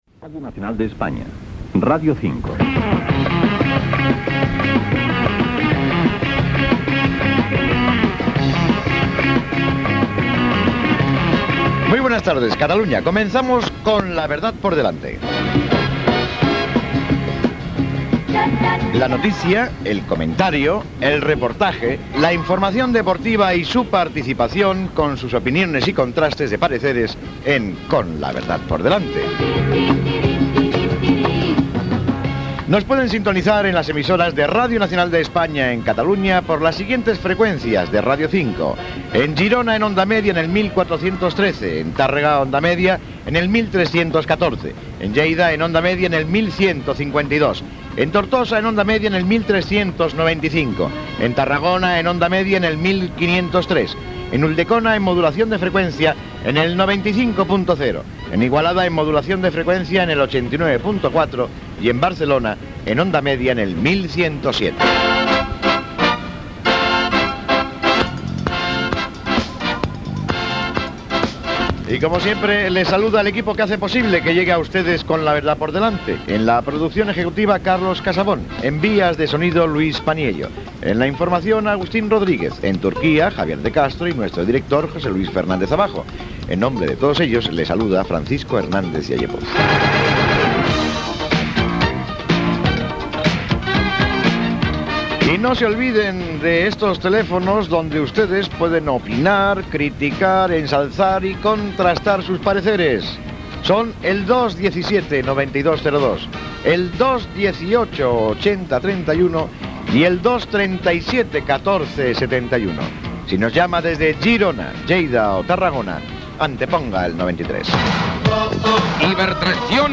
Identificació de l'emissora, freqüències de les emissores de Radio 5 en Ona Mitjana (entre elle Radio 5 Tortosa) i Freqüència Modulada a Catalunya, equip, telèfons, publicitat
Esportiu